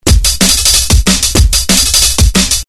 Jungle Loop 2